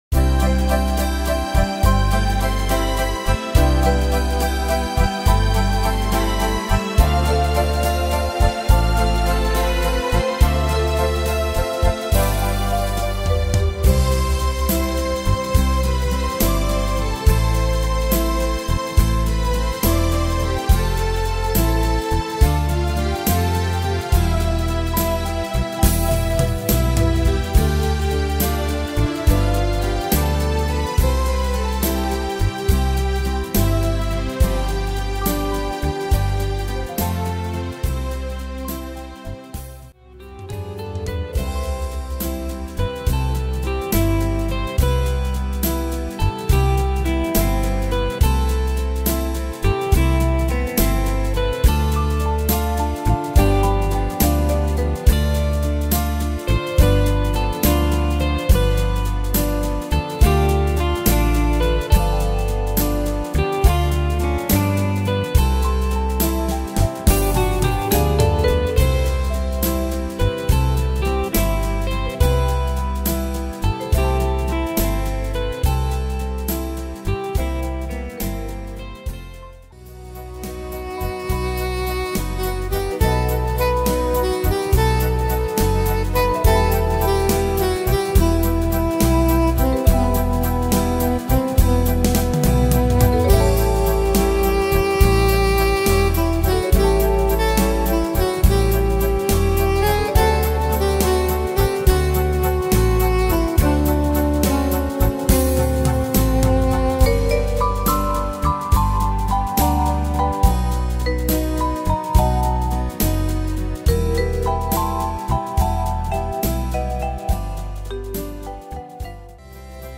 Tempo: 70 / Tonart: C-Dur